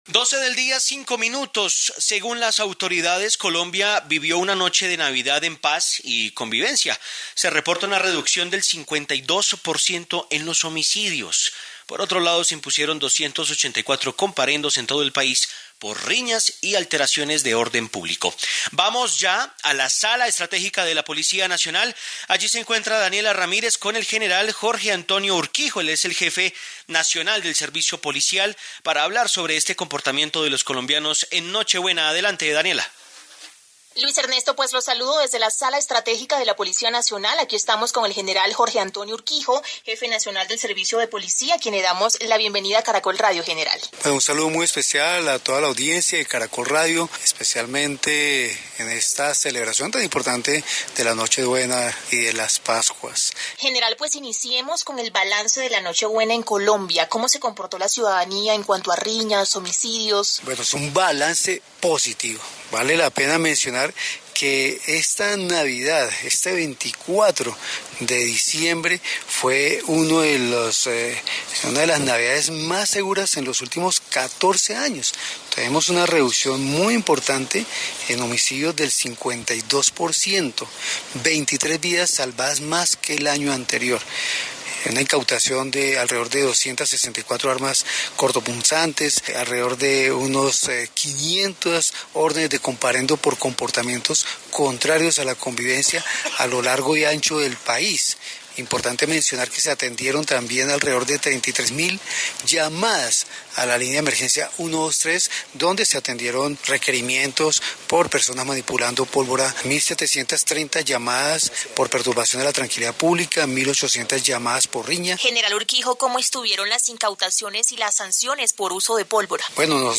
En el noticiero de mediodía de Caracol Radio, el general Jorge Antonio Urquijo Sandoval, Jefe Nacional de Servicio de Policía rechazó estos hechos.